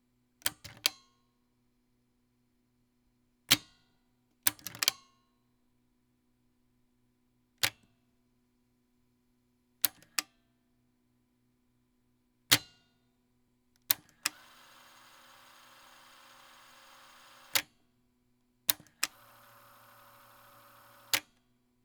メカニズム動作音
• 停止→再生→停止
• 停止→ポーズ→再生→ポーズ→停止
• 早送り・巻戻し